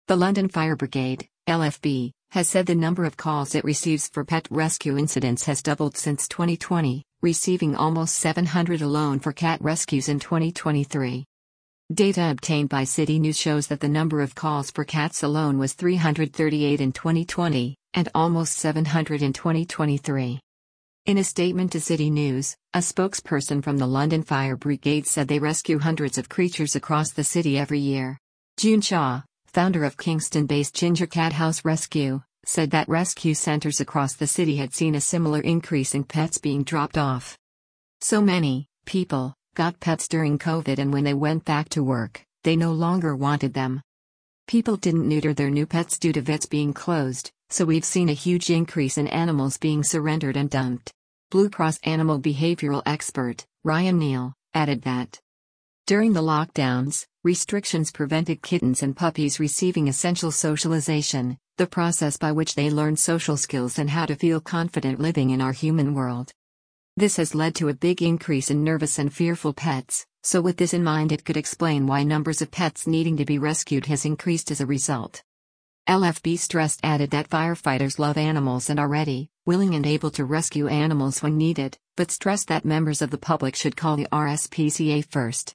Listen to this article powered by AI